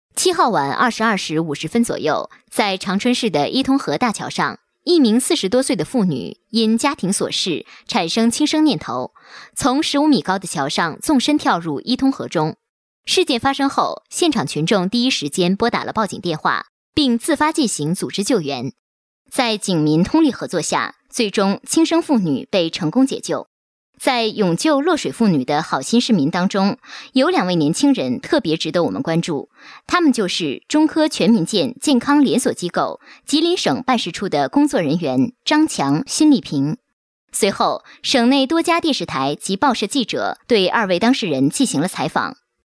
专题配音
配音风格： 激情 大气 亲切 甜美 年轻